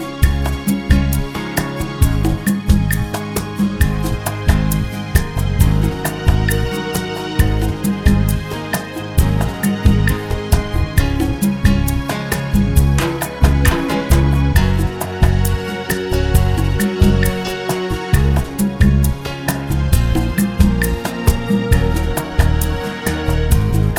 Two Semitones Down Jazz / Swing 3:37 Buy £1.50